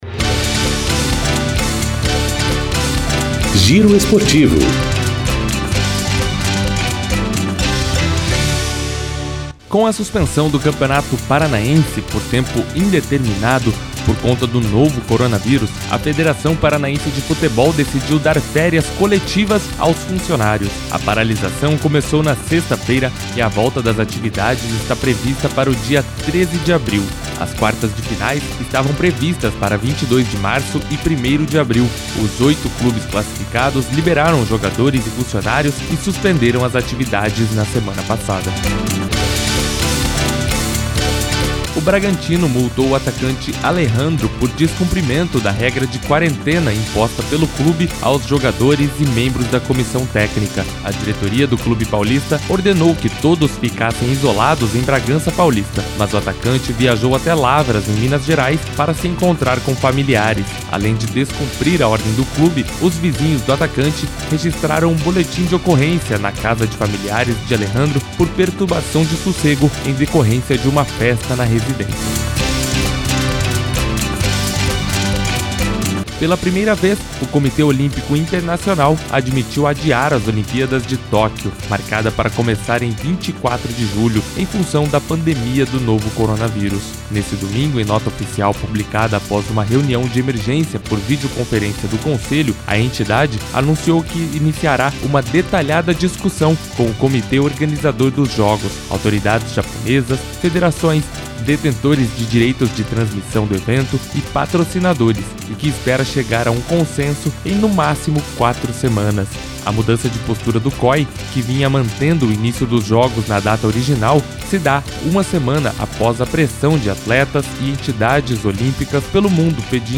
Giro Esportivo COM TRILHA